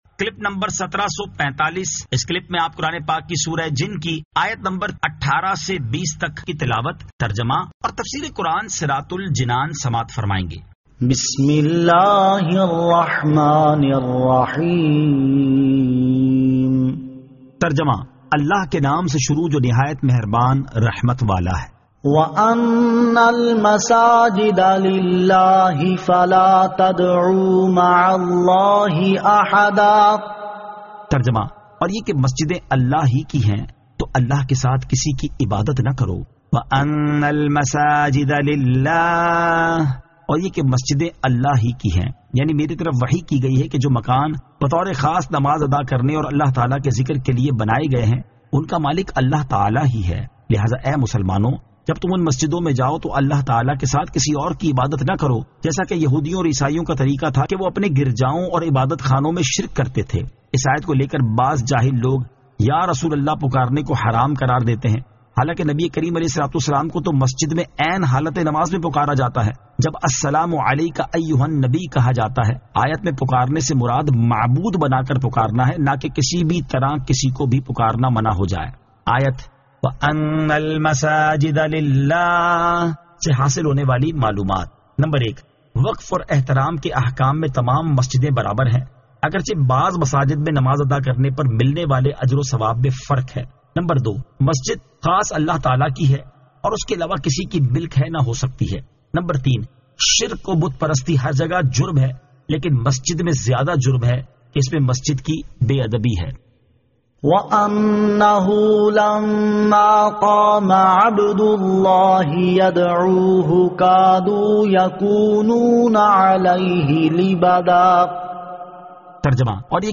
Surah Al-Jinn 18 To 20 Tilawat , Tarjama , Tafseer